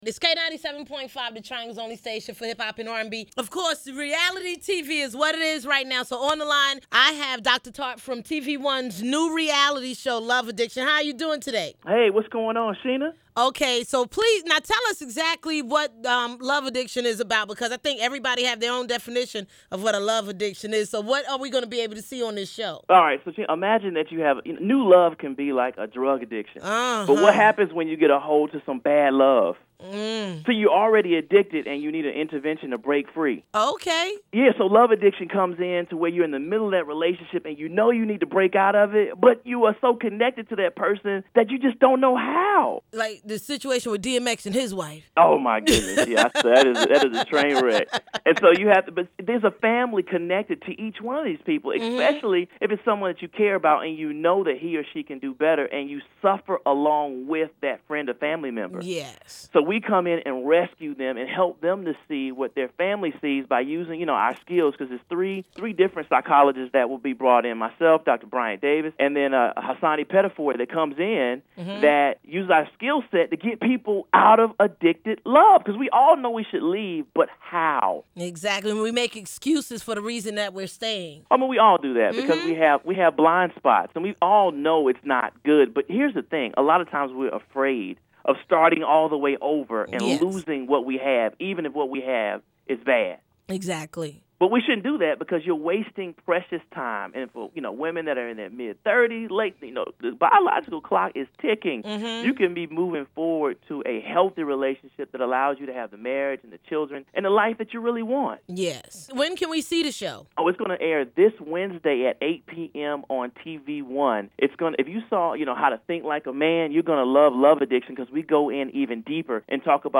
love-addiction-interview.mp3